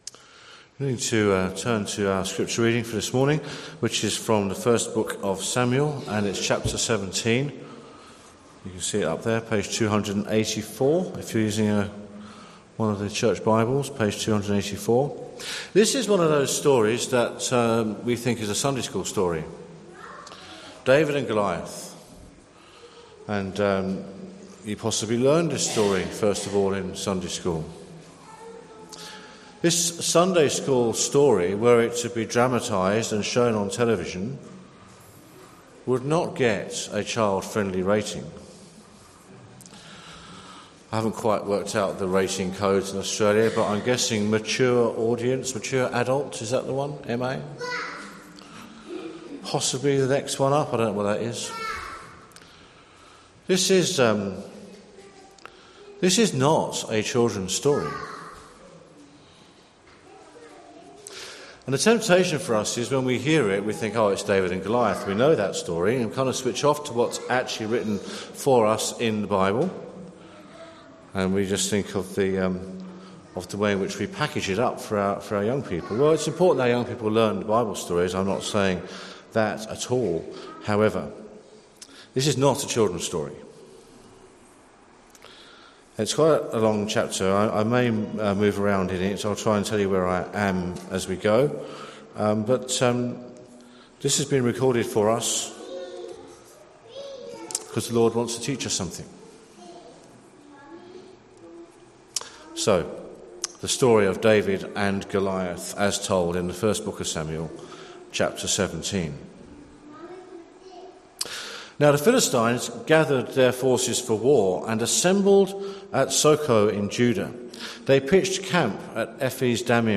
Bible reading and sermon based on 1 Samuel 17 from the 09:30 meeting on 13th September.